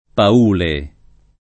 Paule [ pa 2 le ]